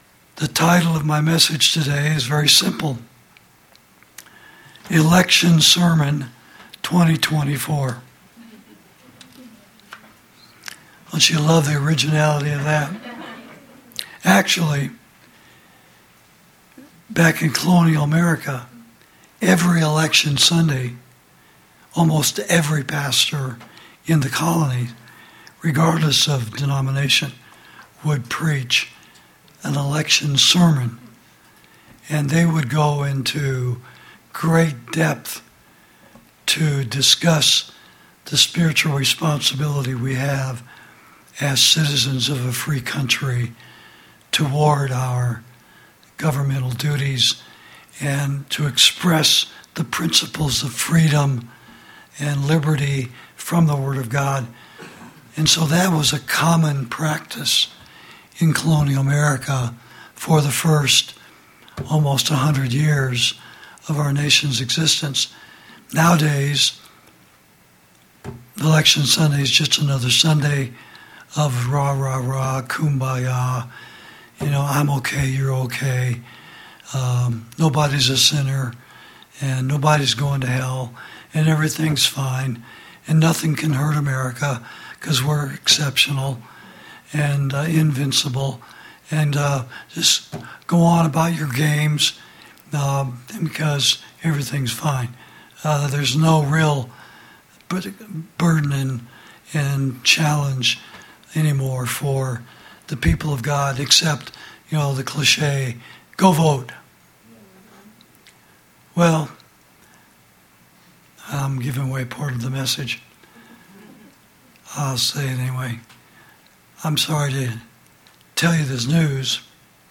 Election Sermon 2024